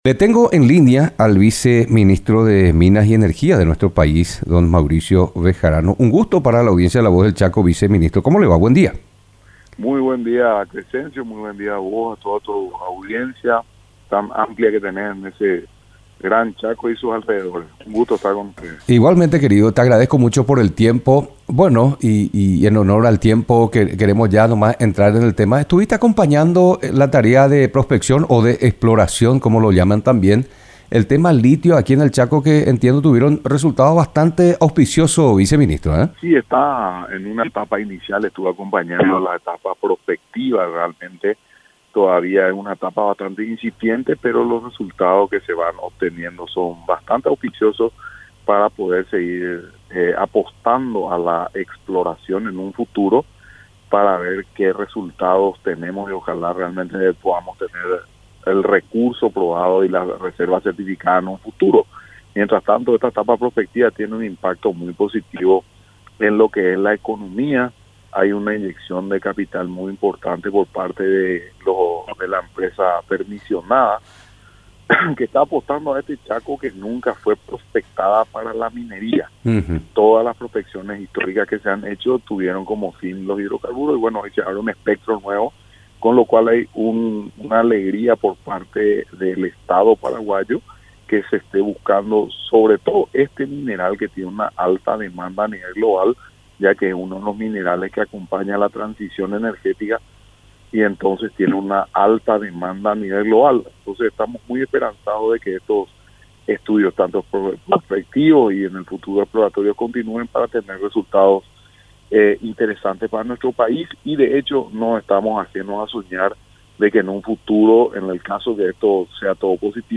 Entrevistas / Matinal 610
Exploración de litio en el Chaco al respecto conversamos con Mauricio Bejarano, viceministro de minas y energía.
Entrevistado: Mauricio Bejarano
Estudio Central, Filadelfia, Dep. Boquerón